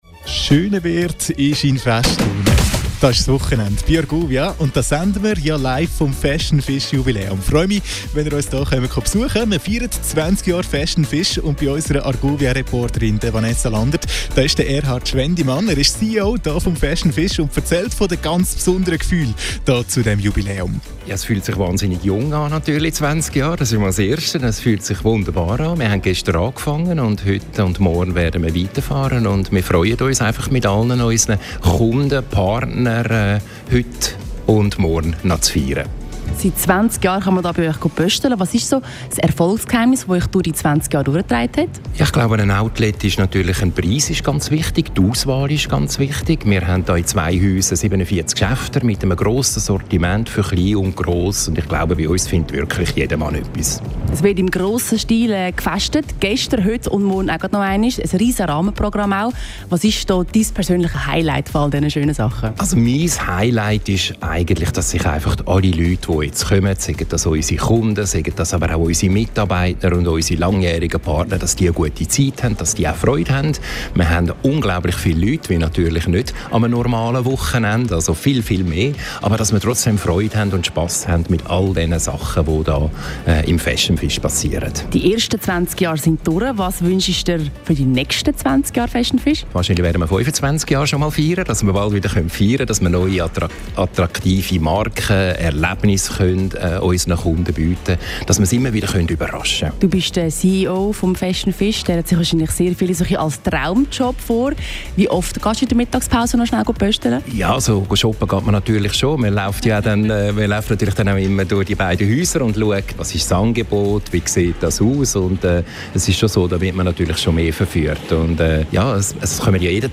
Interview
Radio Argovia live bei Fashion Fish 27.09.2025